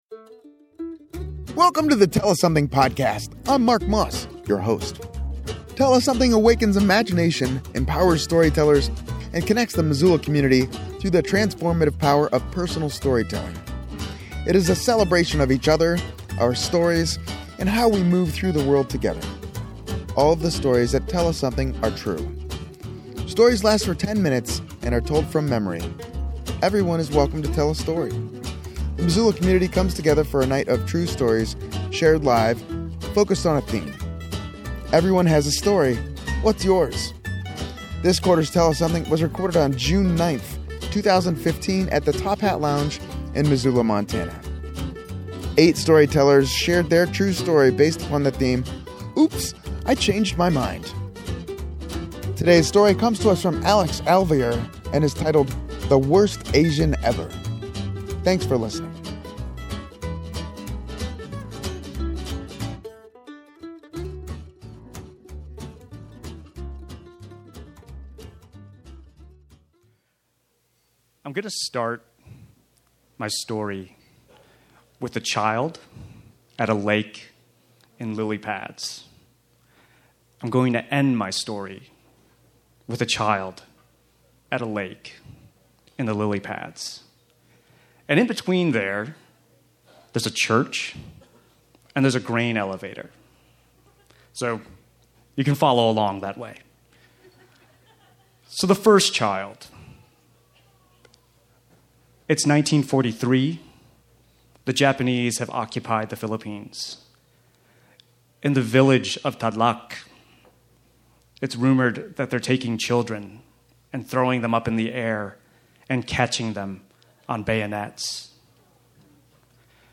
Please join us for an evening of stories based around the theme: “Oops! I Changed My Mind”. Storytellers share their true story.